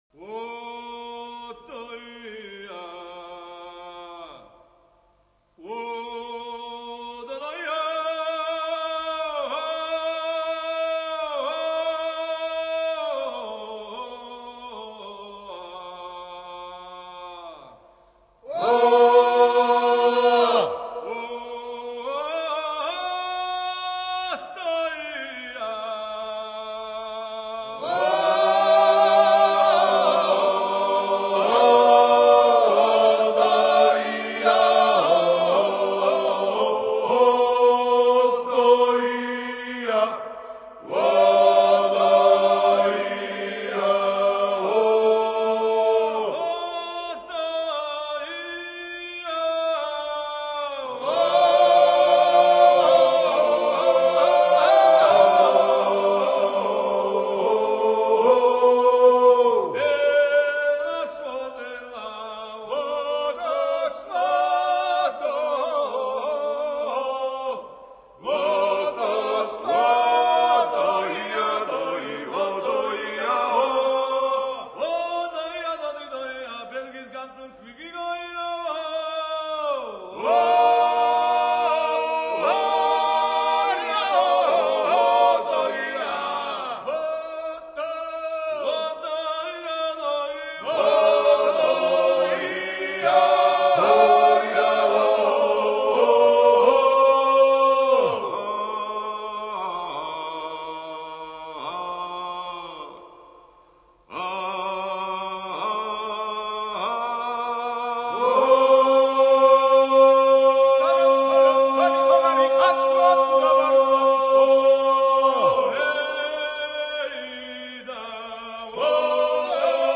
(A toast song wishing many years of life).